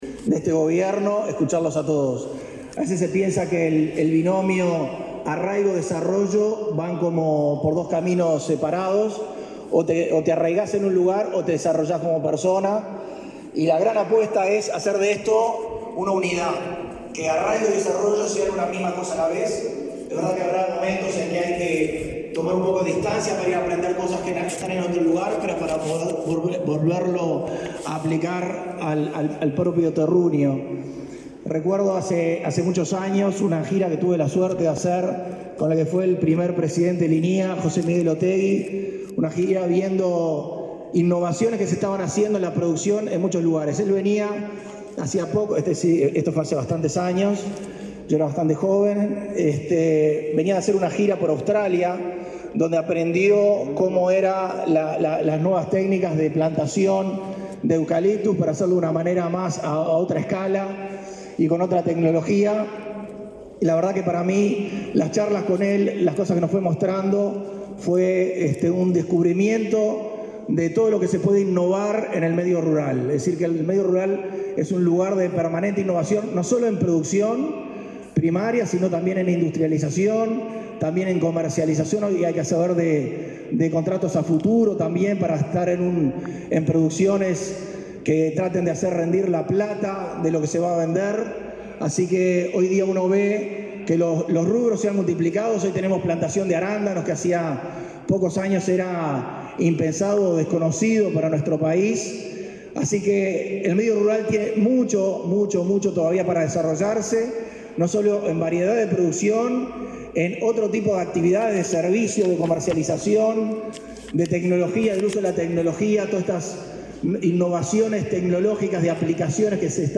Educación, salud, trabajo, producción, cultura y participación serán los ejes de la Comisión Honoraria en el Área de la Juventud Rural, reinstalada este lunes 14 con la presencia de los ministros de Ganadería, Carlos María Uriarte, y Desarrollo Social, Pablo Bartol; el subdirector de OPP, José Luis Falero; el director de Colonización, Julio César Silveira, y el director del Instituto Nacional de la Juventud, Felipe Paullier.